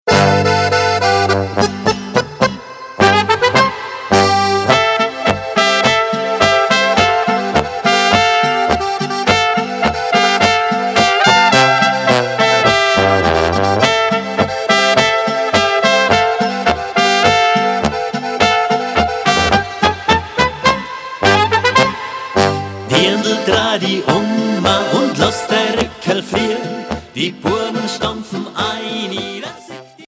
Boarisches Lied